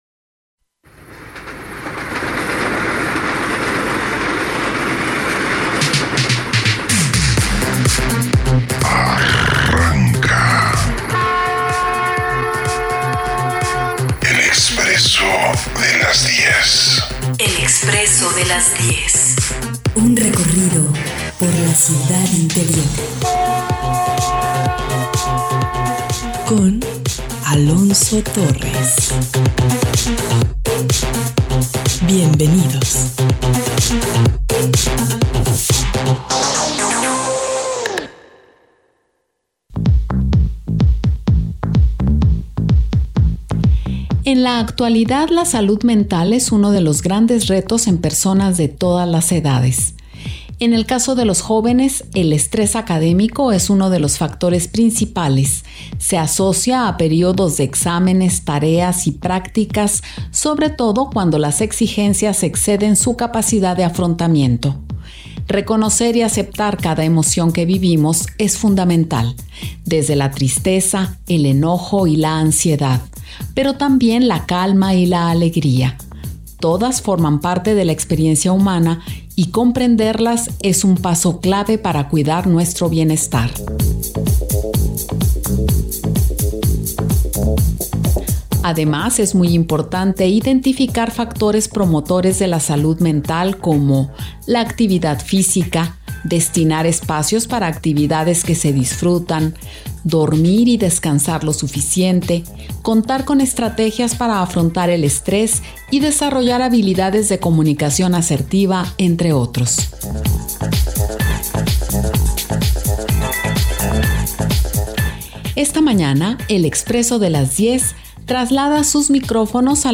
Salud mental en la prepa, Trasmisión especial desde la Preparatoria No. 5 - El Expresso de las 10 - Vi. 06 Febrero 2026